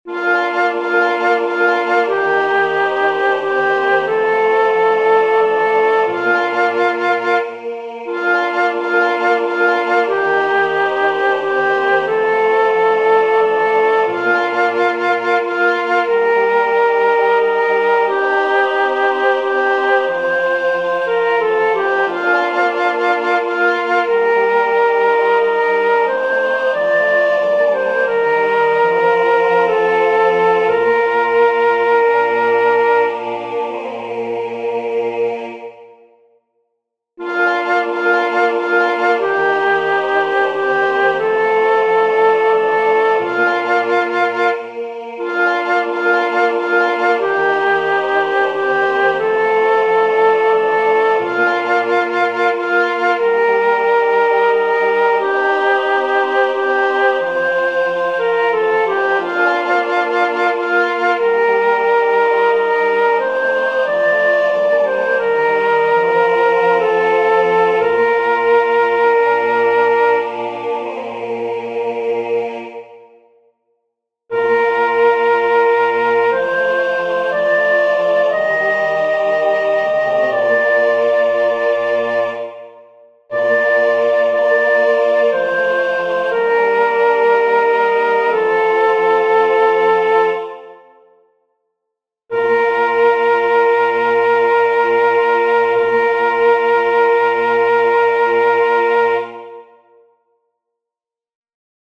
Para aprender la melodía os dejo los enlaces a los MIDIS según la primera versión que os expliqué más arriba, es decir, con el tenor a entrando a un tiempo de espera.
La melodía es muy sencilla y fácil de aprender.
ave_vera_virginitas-soprano.mp3